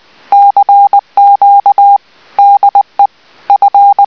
LA TELEGRAPHIE, LE MORSE (CW)
- 20 mots/mn